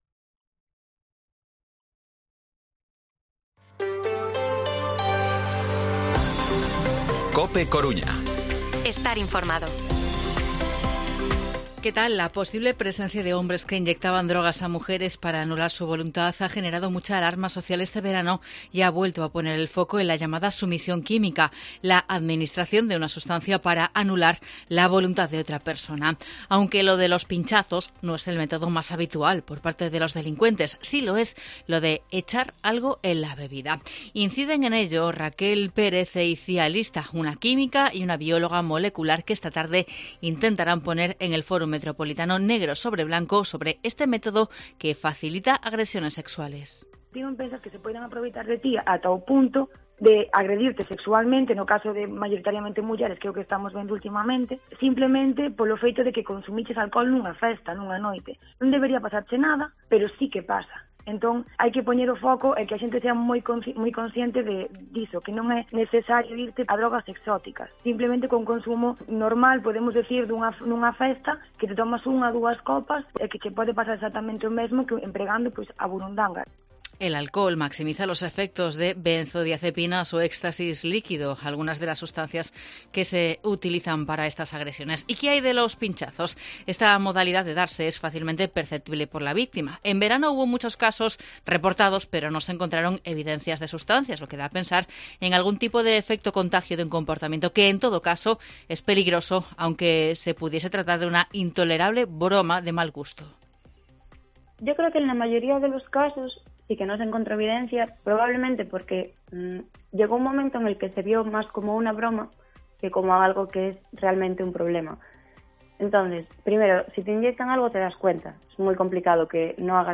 Informativo Mediodía COPE Coruña lunes, 31 de octubre de 2022 14:20-14:30